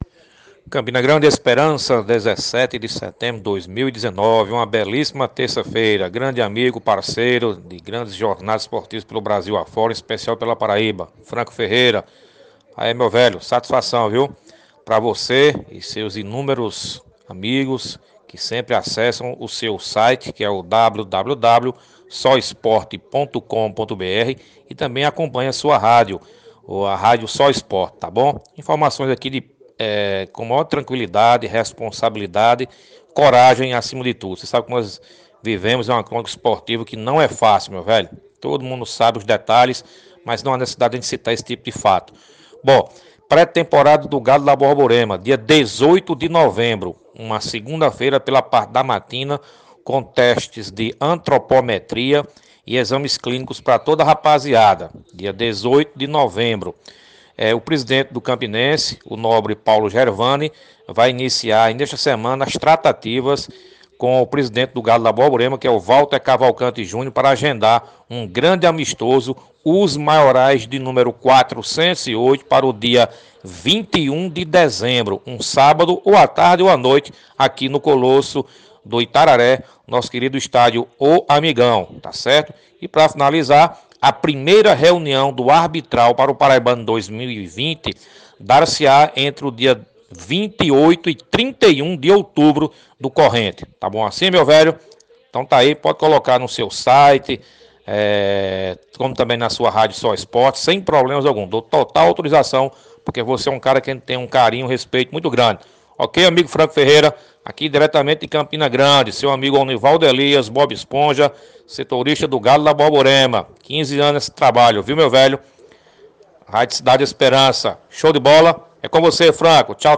Os detalhes com o repórter